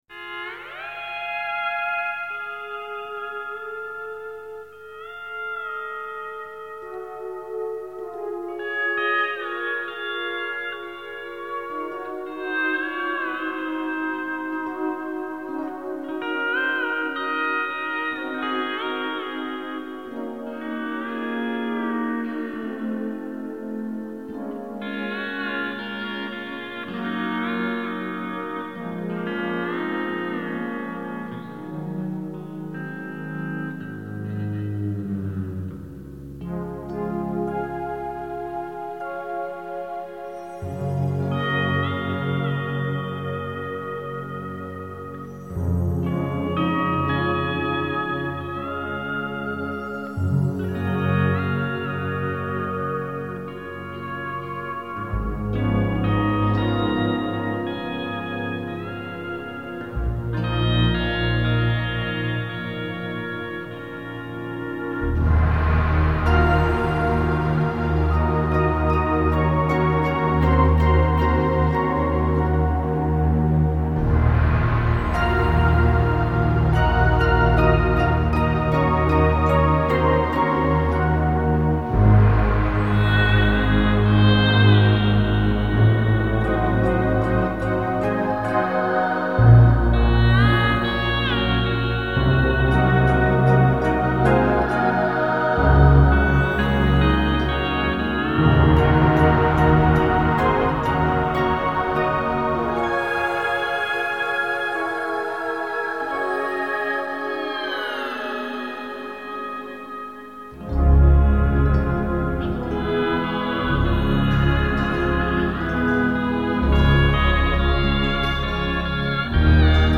pedal steel guitar